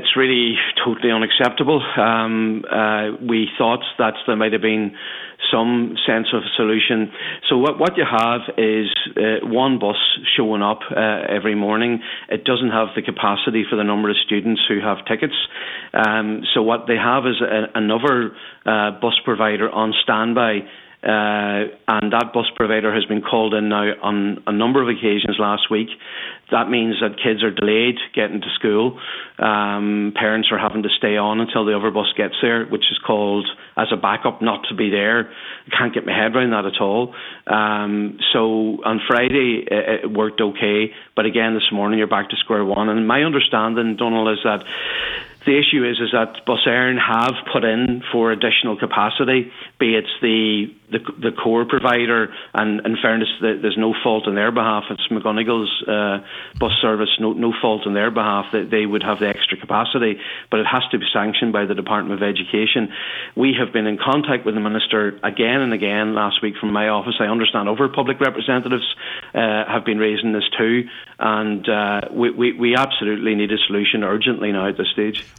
Pádraig Mac Lochlainn TD says this is simply not acceptable..